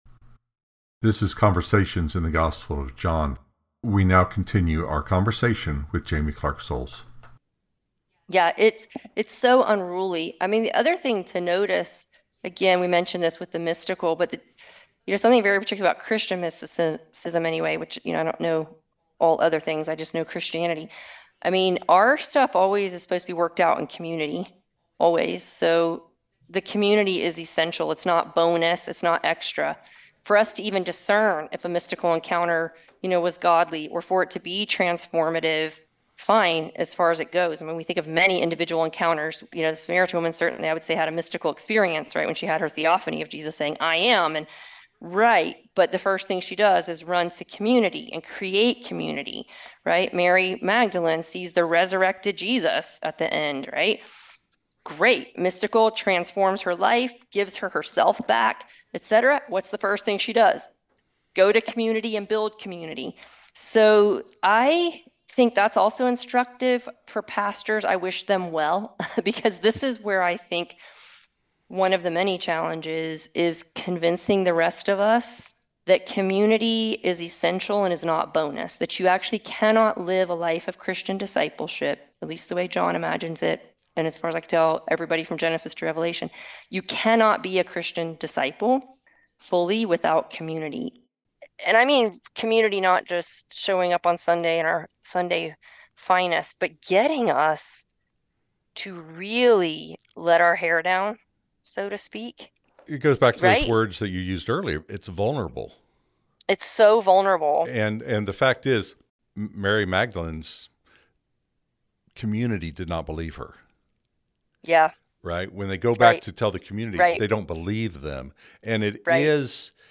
Episode 10 | Podcasts | Conversations in the Gospel of John